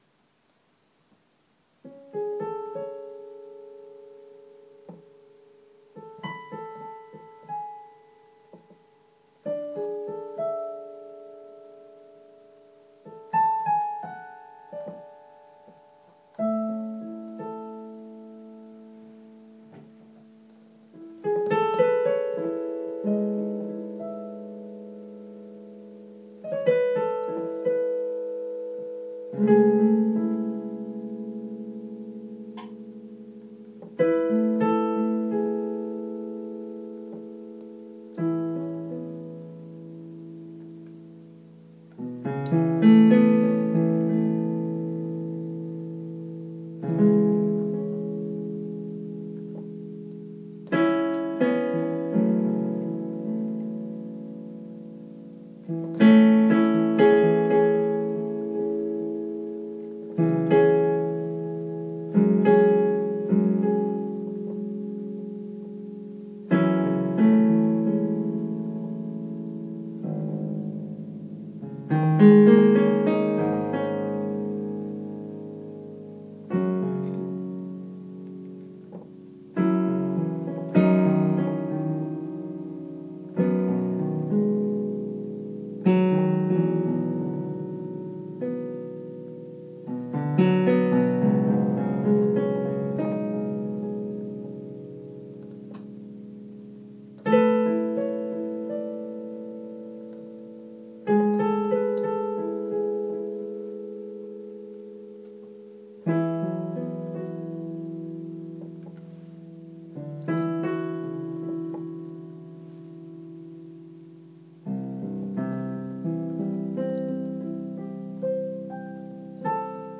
Latenightpiano